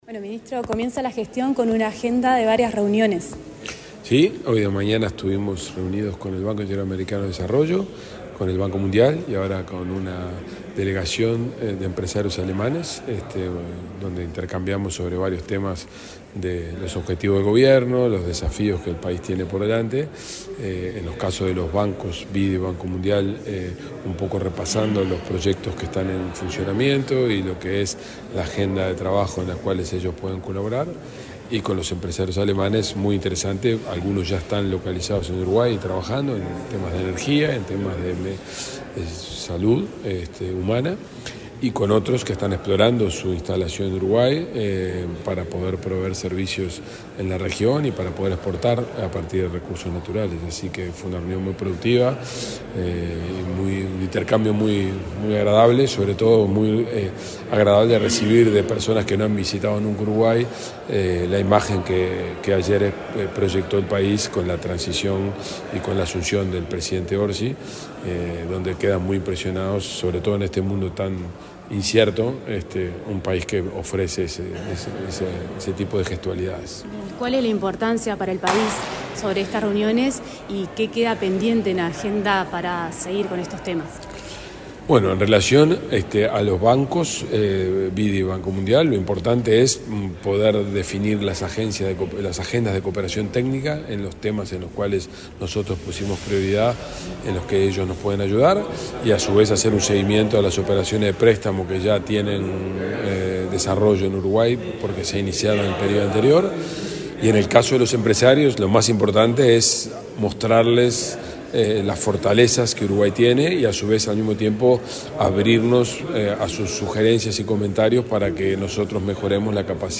Entrevista al ministro de Economía, Gabriel Oddone
Entrevista al ministro de Economía, Gabriel Oddone 02/03/2025 Compartir Facebook X Copiar enlace WhatsApp LinkedIn Este domingo 2 en la Cancillería, el ministro de Economía, Gabriel Oddone, dialogó con la Presidencia de la República, luego de reunirse con autoridades de organismos bilaterales.